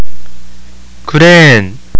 有聲發音